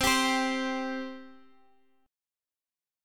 C5 Chord
Listen to C5 strummed